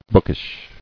[book·ish]